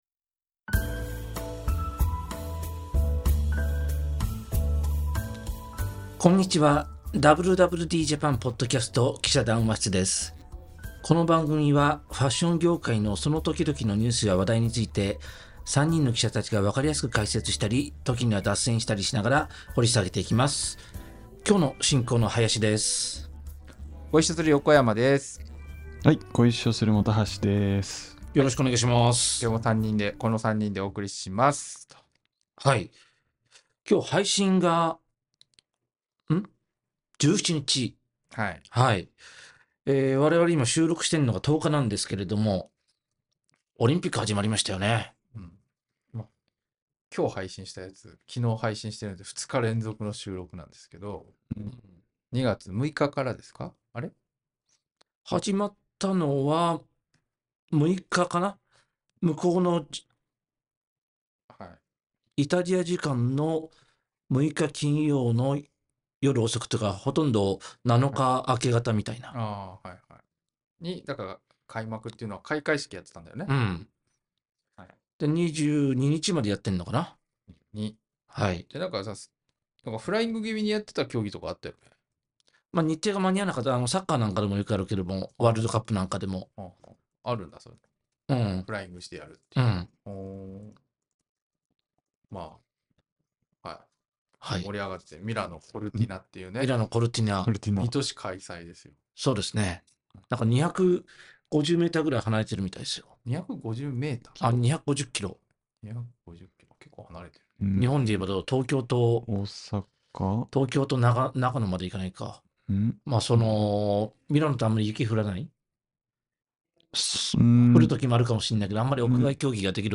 【連載 記者談話室】 「WWDJAPAN」ポッドキャストの「記者談話室」は、ファッション業界のその時々のニュースや話題について、記者たちが分かりやすく解説したり、時には脱線したりしながら、掘り下げていきます。